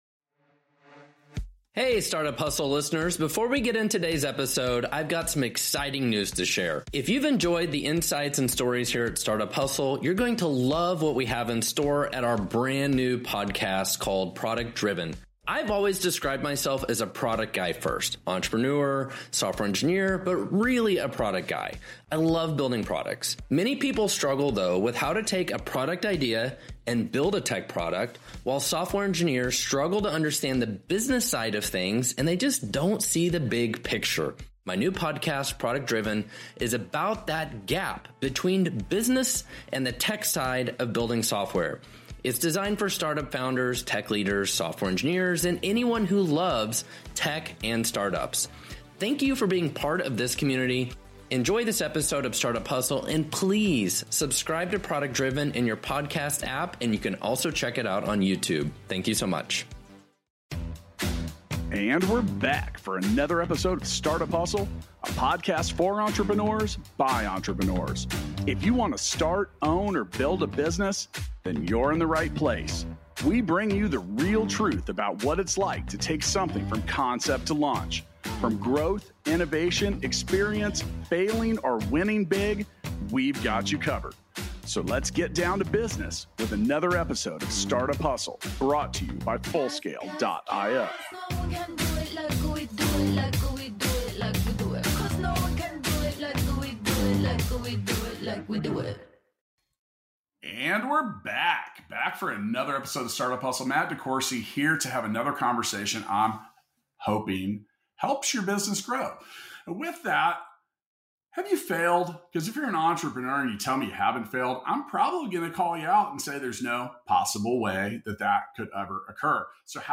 From CEO to CEO, the duo shares their insights on the value of taking responsibility for your failure.